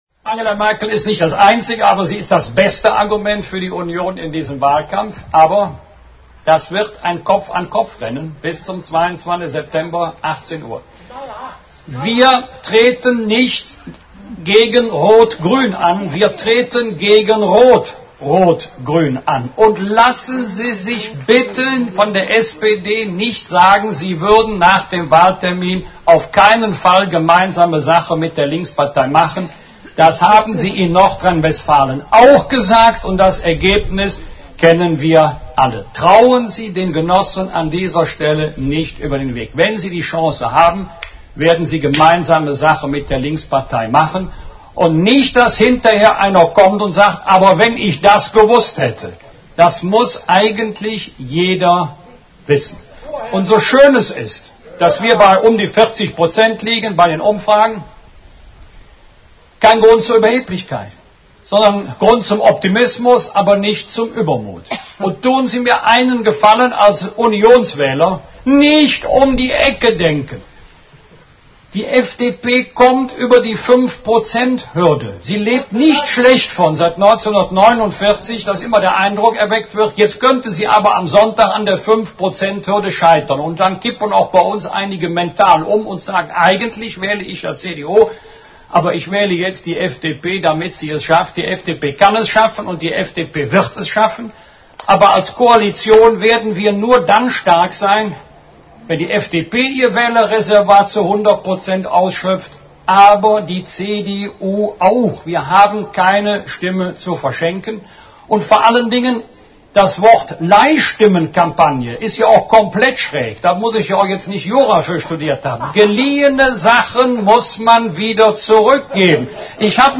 BürgerZeitung für Mönchengladbach und Umland 1.0 » Blog Archiv » Wolfgang Bosbach „Zugpferd“ beim CDU-Wahlkampfauftakt auf dem Hoeren-Hof in Raderbroich [mit O-Tönen & Slideshow]
Und genau das versuchte er auch am Freitagnachmittag auf einer Wiese mit Obstbäumen neben dem Hoerenhof in Raderbroich.
Launig sprach er sich gegen so genannte Leihstimmen an die FDP aus.